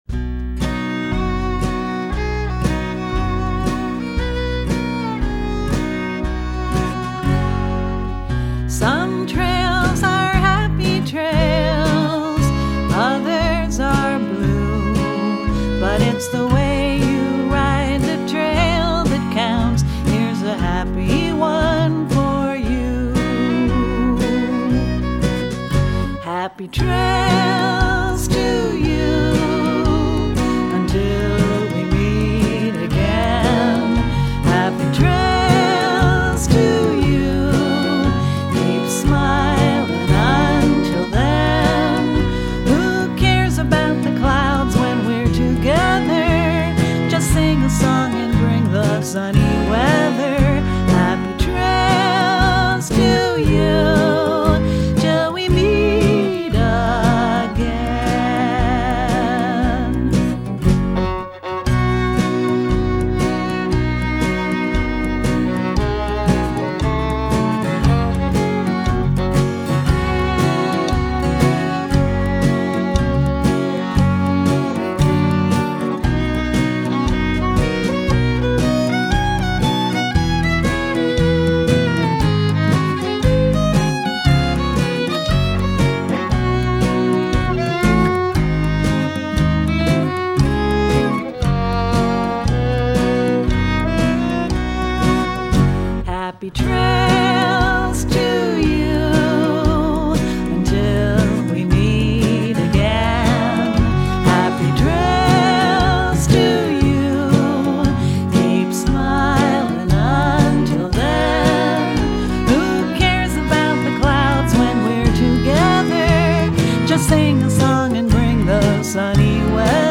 the subtle arrangements of fiddle and guitar